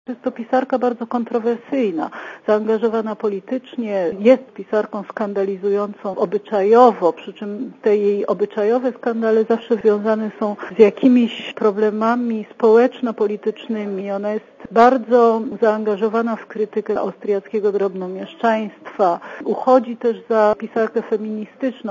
Dla radia ZET mówi profesor